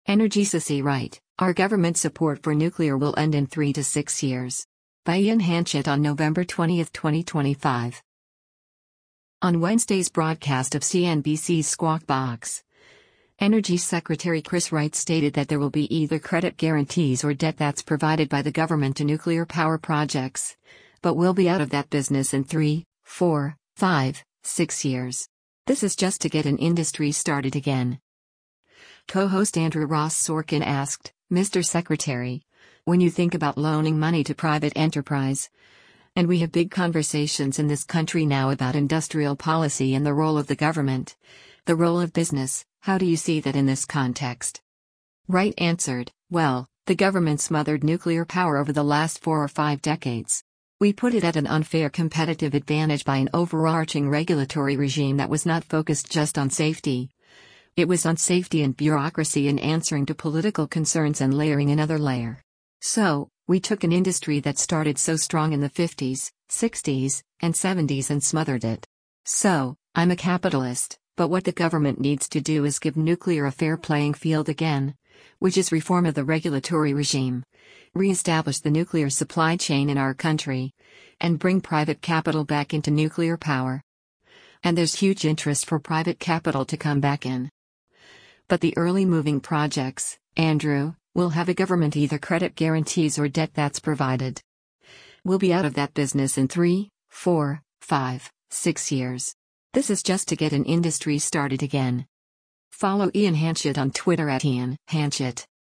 On Wednesday’s broadcast of CNBC’s “Squawk Box,” Energy Secretary Chris Wright stated that there will be “either credit guarantees or debt that’s provided” by the government to nuclear power projects, but “We’ll be out of that business in three, four, five, six years. This is just to get an industry started again.”